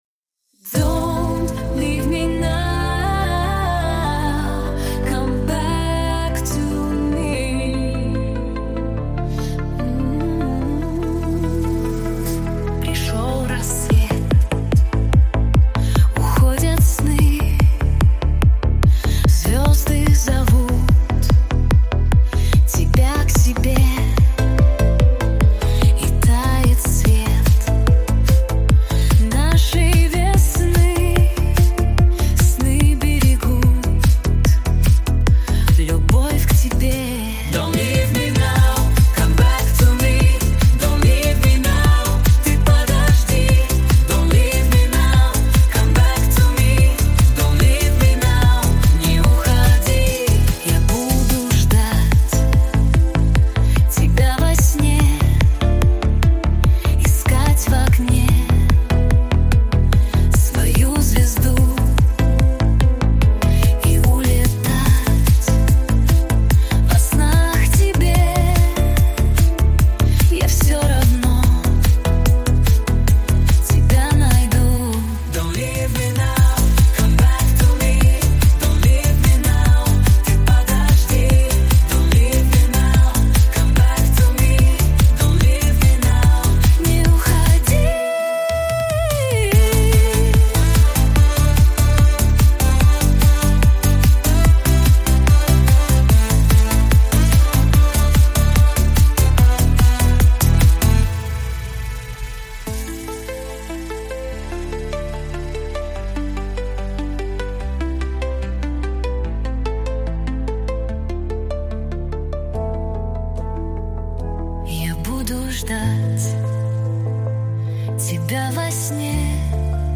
pop , диско